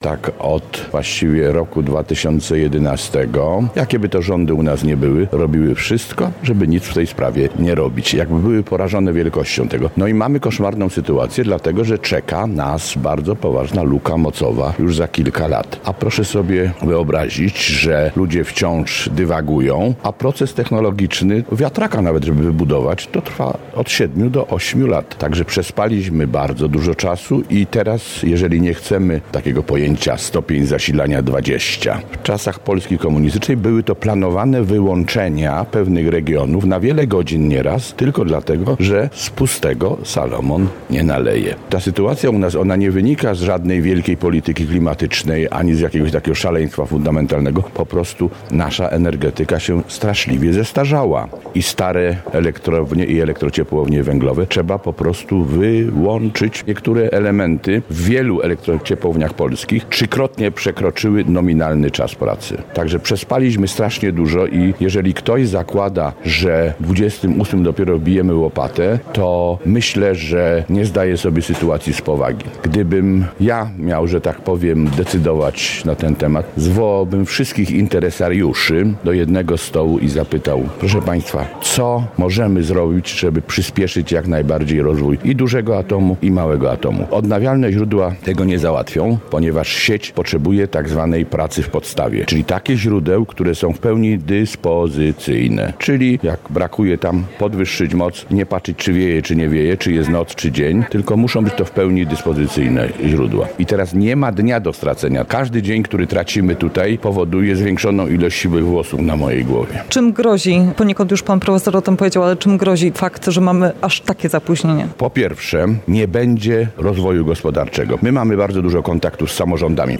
mówi w rozmowie